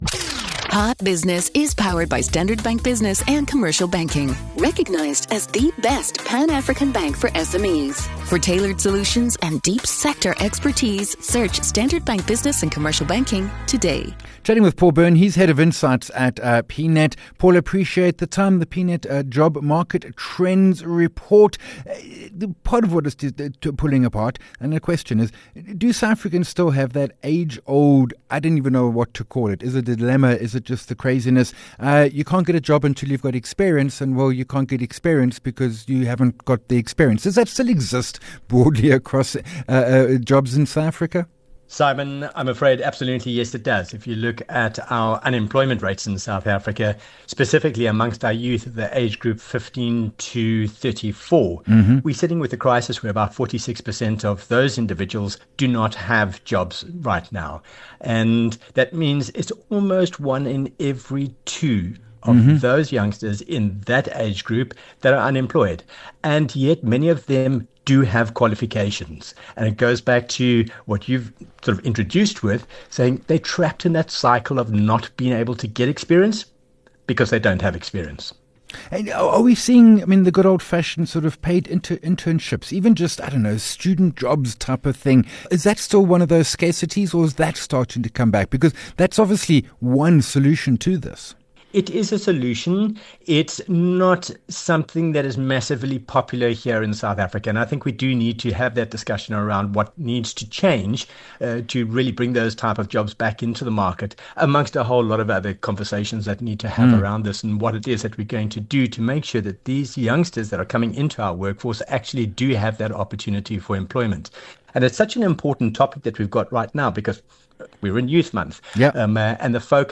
11 Jun Hot Business Interview